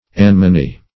anemony - definition of anemony - synonyms, pronunciation, spelling from Free Dictionary Search Result for " anemony" : The Collaborative International Dictionary of English v.0.48: Anemony \A*nem"o*ny\, n. See Anemone .
anemony.mp3